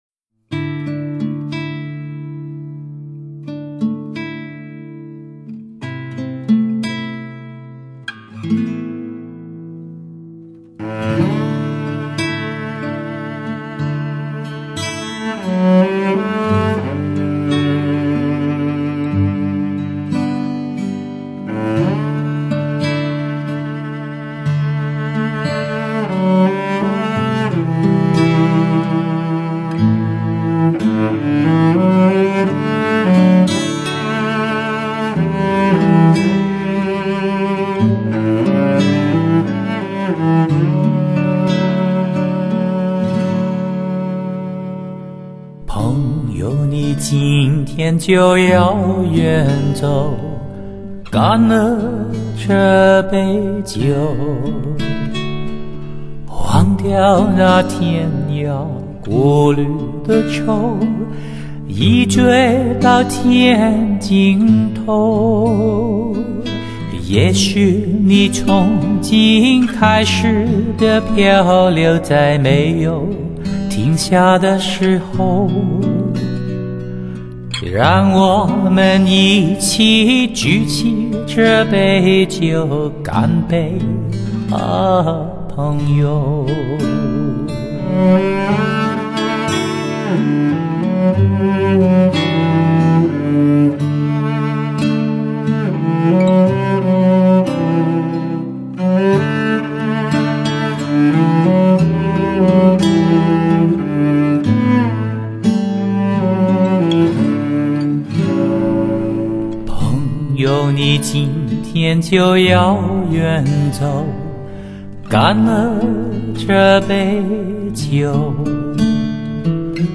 纯净音乐，优美歌声，重温美好时光